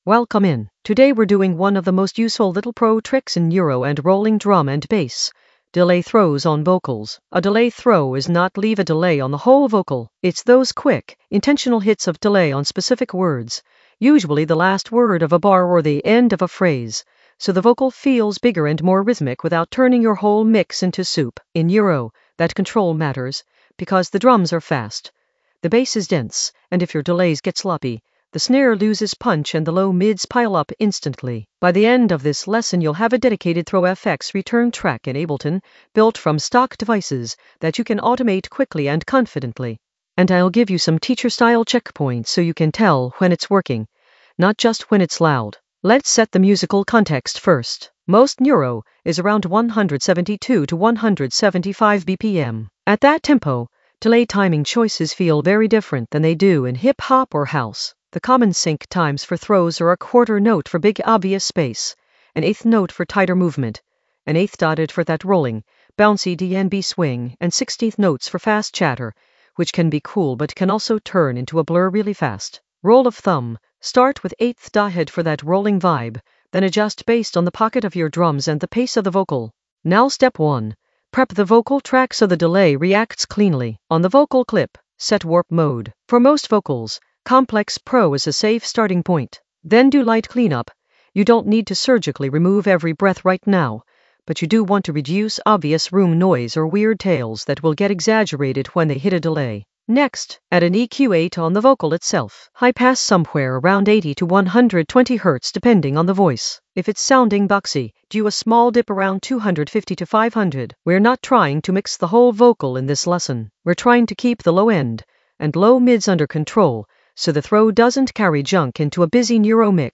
An AI-generated beginner Ableton lesson focused on Delay throws on vocals for neuro in the FX area of drum and bass production.
Narrated lesson audio
The voice track includes the tutorial plus extra teacher commentary.